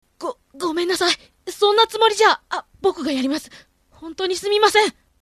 声質は、赤ちゃんからおばあちゃんまで幅が広く、使い出のよい声です。
以下のサンプルは自宅収録です。音質はこれくらいのものを提出できます。
少年（　約束